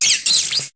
Cri de Togedemaru dans Pokémon HOME.